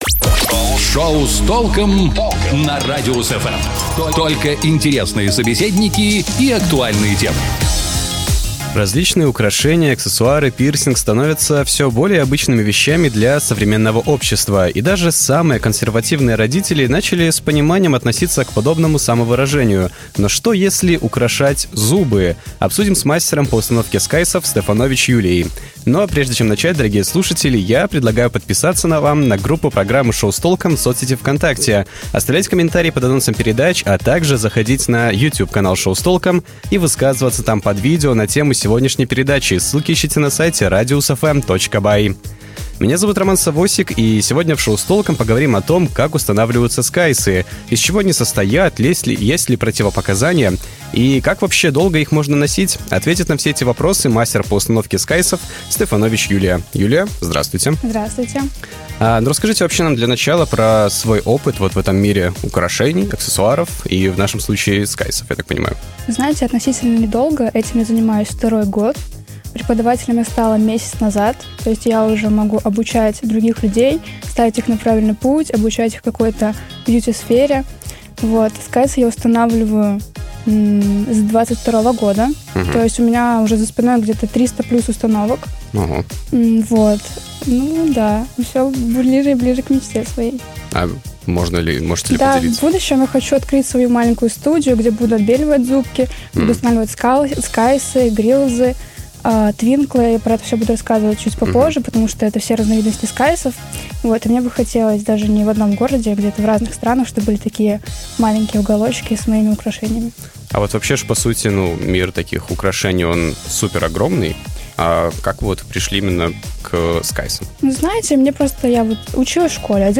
Ответит на все эти вопросы мастер по установке скайсов